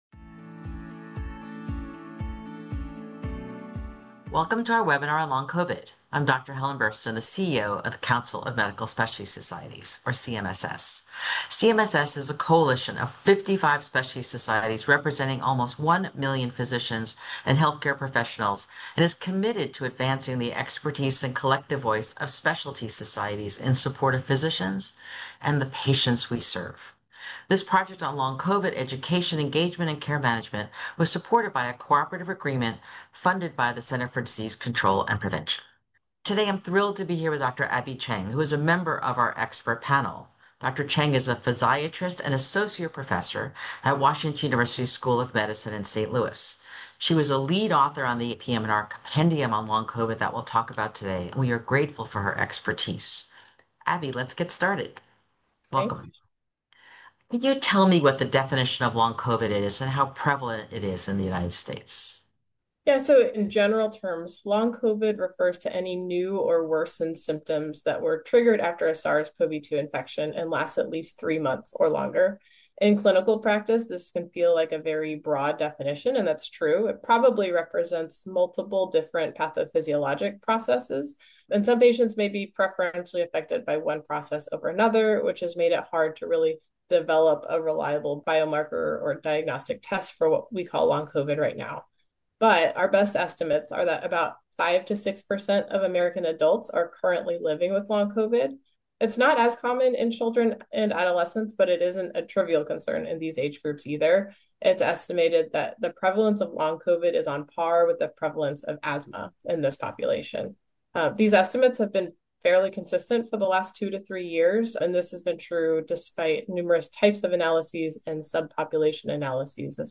Long COVID: CMSS Interview with AAPM&R